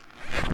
Skweaks
skweak2.ogg